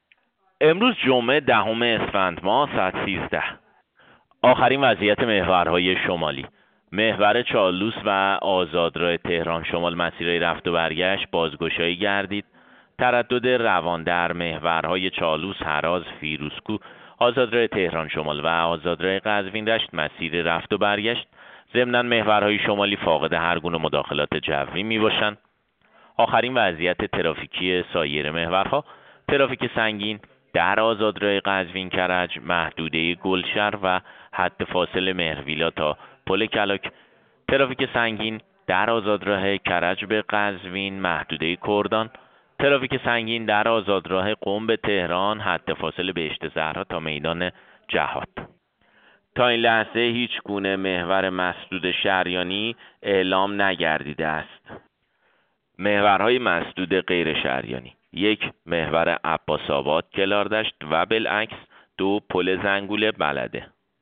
گزارش رادیو اینترنتی از آخرین وضعیت ترافیکی جاده‌ها ساعت ۱۳ دهم اسفند؛